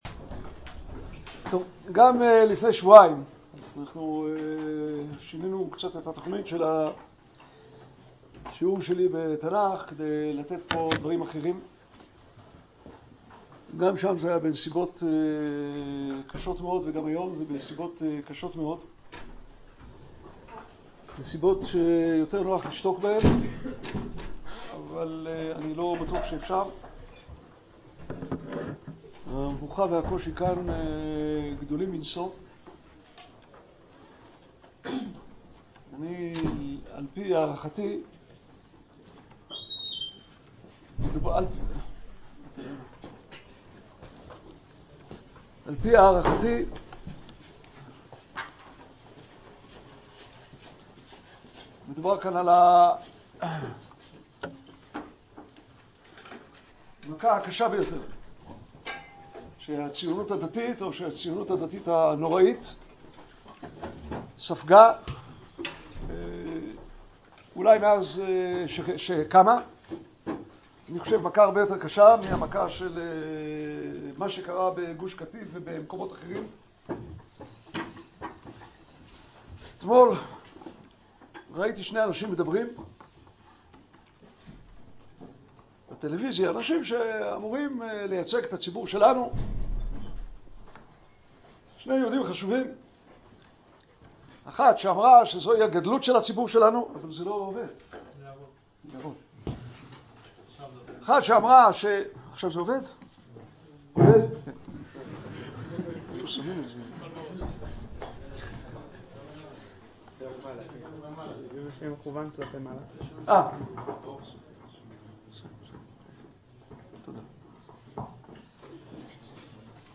לדעת לקום | שיחה בעקבות פרשת הרב מוטי אלון | תורת הר עציון